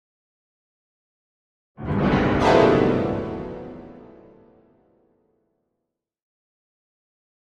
Piano Fast Ascending Tension - Medium